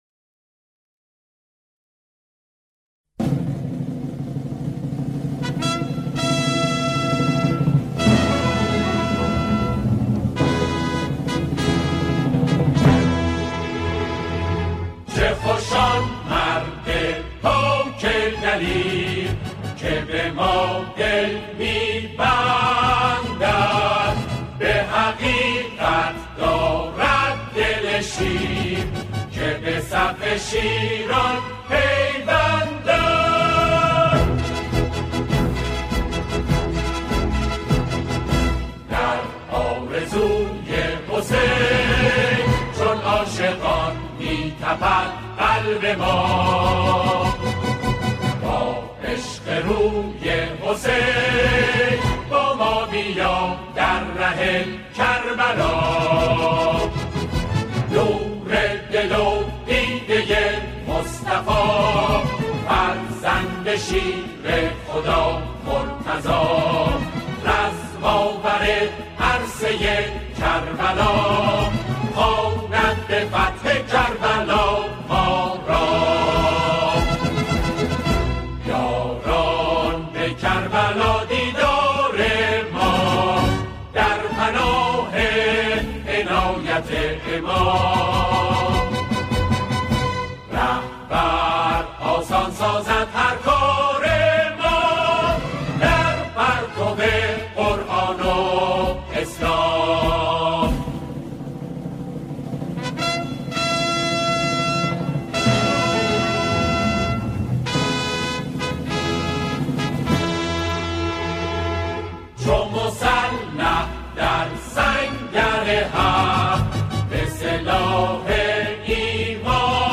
سرود قدیمی